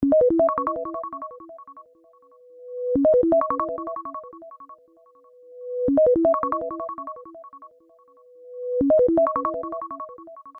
на будильник
без слов